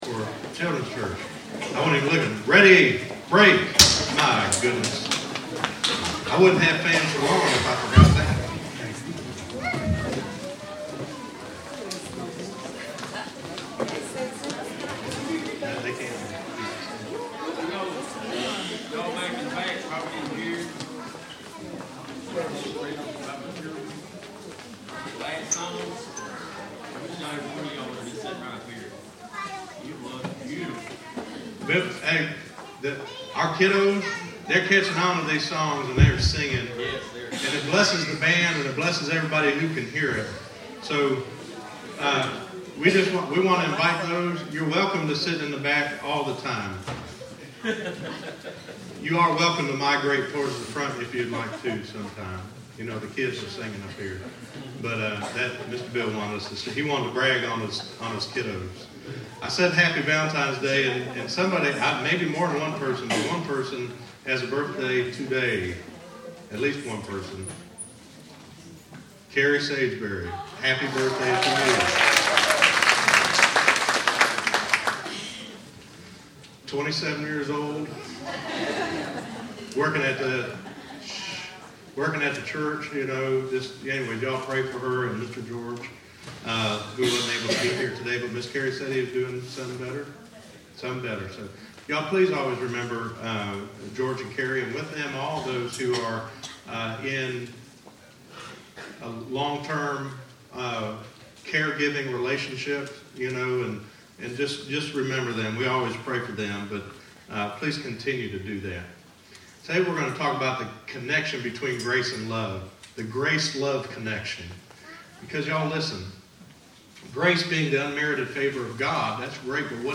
Listen to The GRACE LOVE Connection - 02_14_16_Sermon.mp3